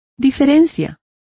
Complete with pronunciation of the translation of discrepancy.